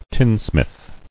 [tin·smith]